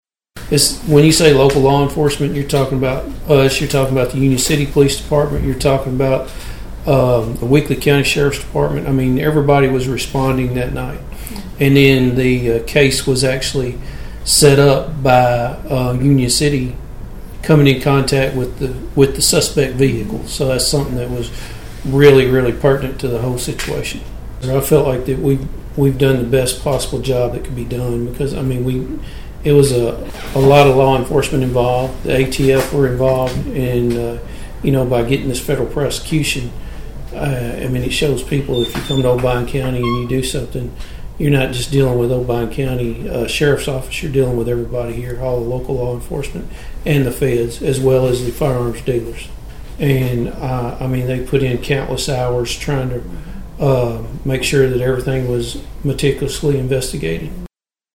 Obion County Sheriff Karl Jackson explained all of the resources involved in the case.(AUDIO)